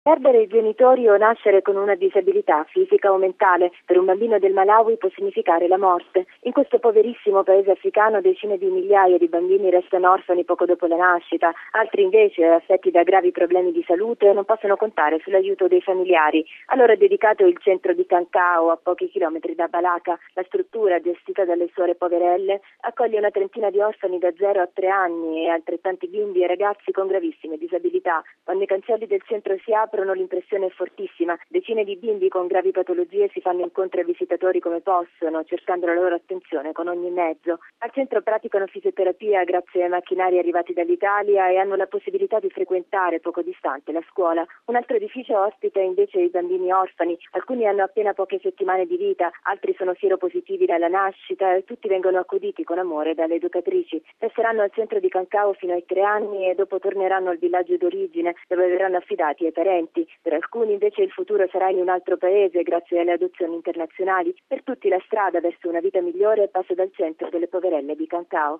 ◊   Nel Malawi flagellato da Aids e malattie, un centro gestito da religiose assiste gli orfani e i bambini disabili, i più fragili in assoluto. Il servizio